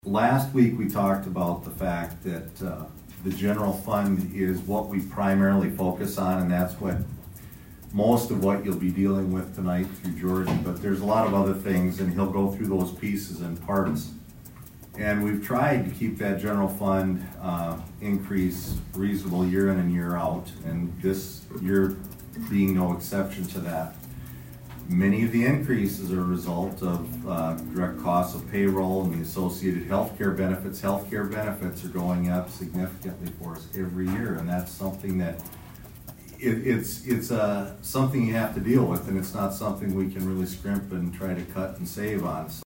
ABERDEEN, S.D.(HubCityRadio)- On Monday night, the Aberdeen City Council did a work session to address the budget for the year 2026.